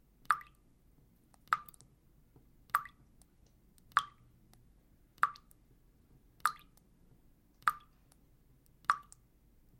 Шепот капель в ванной комнате